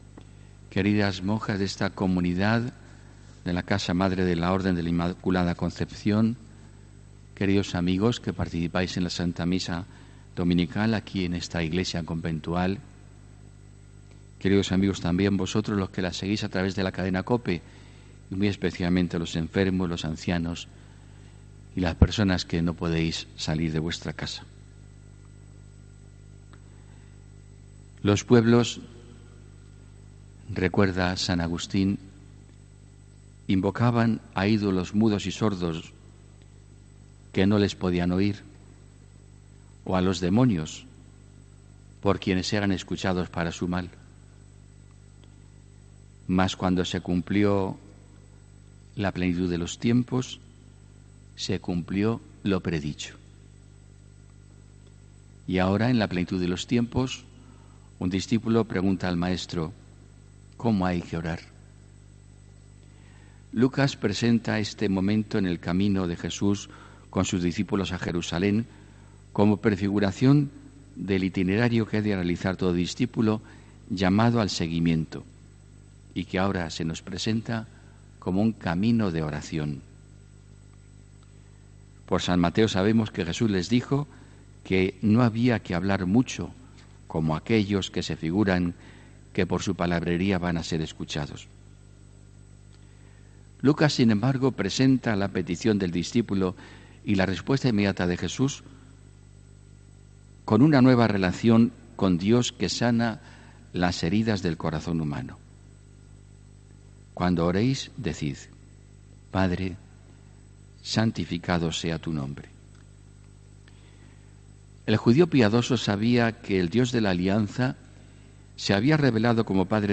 HOMILÍA 28 JULIO 2019